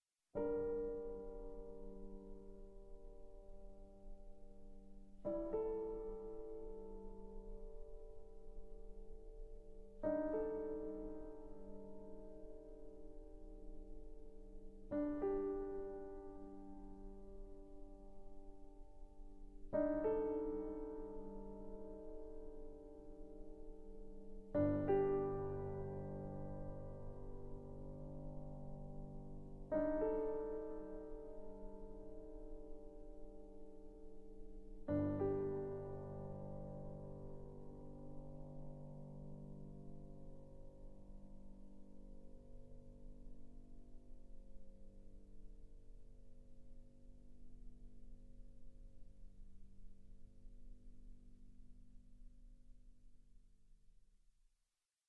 haunting original score
It’s some of the darkest music I’ve ever heard.